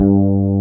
BAS.FRETG2-L.wav